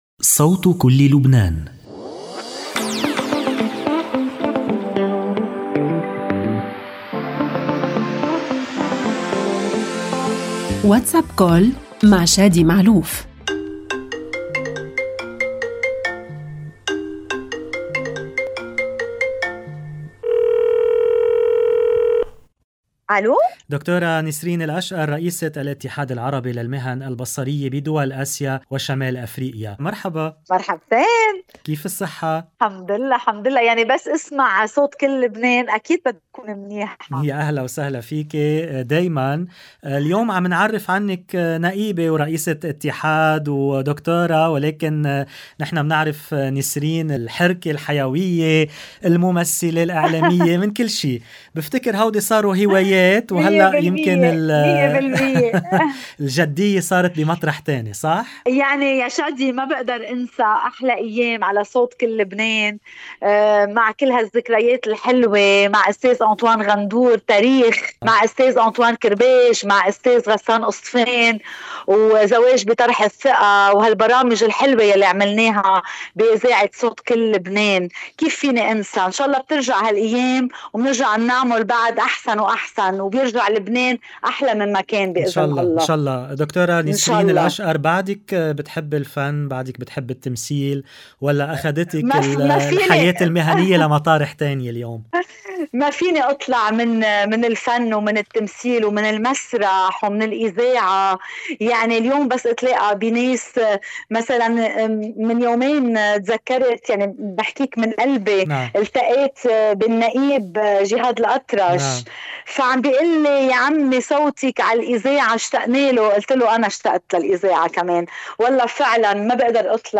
WhatsApp Call